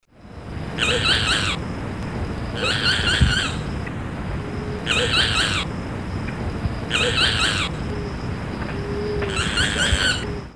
Quando o neinei começa a cantar não tem jeito, ele toma conta dos nossos ouvidos. A vocalização é repetitiva, insistente e desvia a nossa atenção das outras aves, tem que tomar cuidado para não ficar irritado.
Se você duvida que o neinei é insistente
ouça aqui, imagine 15, 20, 30 minutos ouvindo seguidamente neinei, neinei...